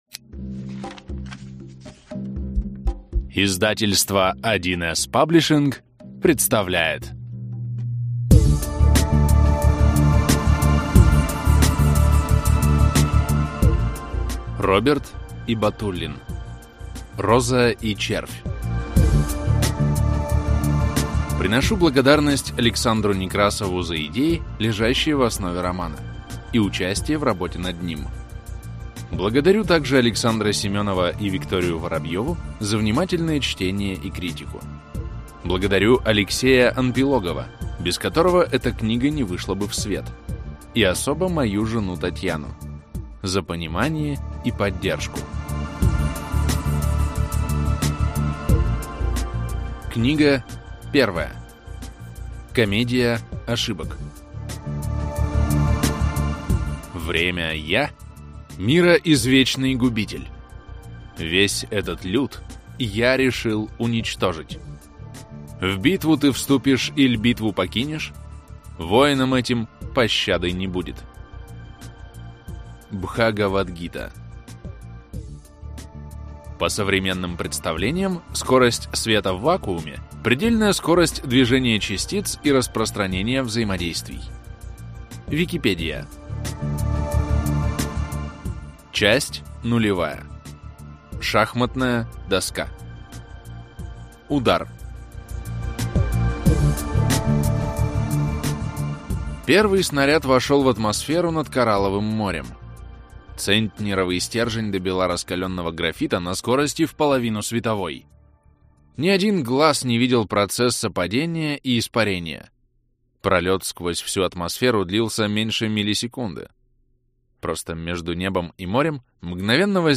Аудиокнига Роза и червь - купить, скачать и слушать онлайн | КнигоПоиск